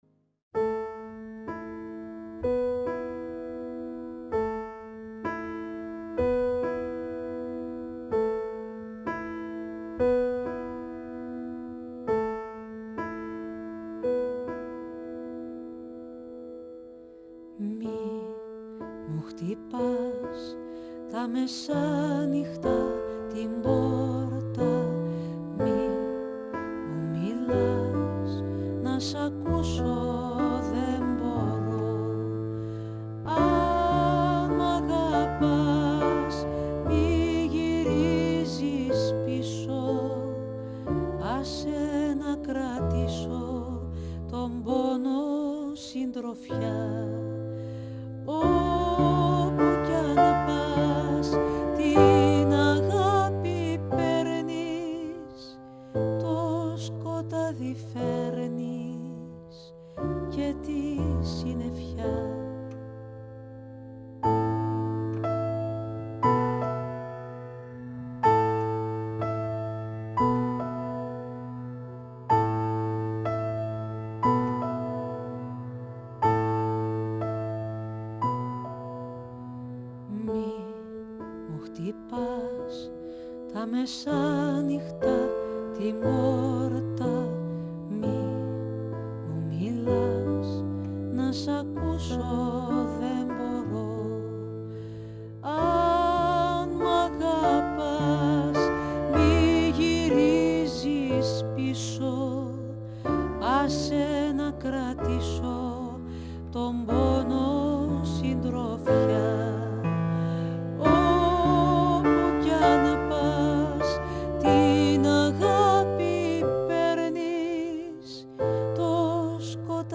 Μετά την συνεντευξη ακούγεται το διήγημα “Γυαλένιο μάτι” του Ναπολέοντα Λαπαθιώτη με την αφήγησή της Η συνέντευξη πραγματοποιήθηκε τη Δευτέρα 14 Δεκεμβρίου 2020 “Καλημέρα” στον 9,58fm ΕΡΤ3 Eπιμέλεια-παρουσίαση